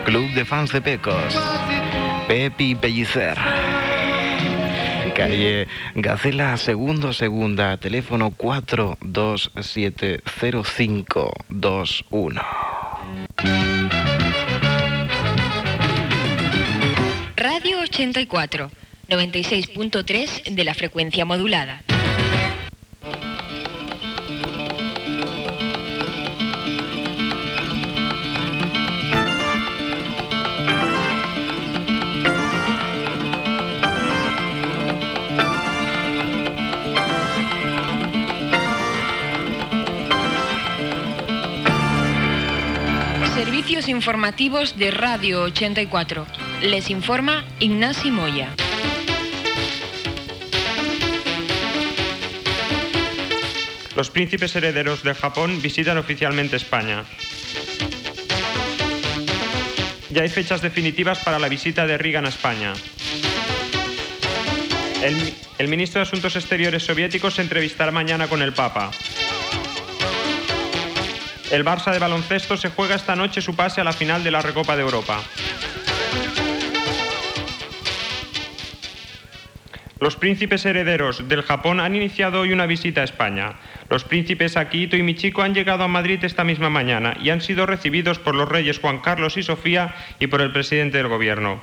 Club de fans de Pecos, indicatiu, informatiu: visita dels prínceps hereus del Japó a Espanya.
Informatiu